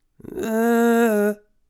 Index of /99Sounds Music Loops/Vocals/Melodies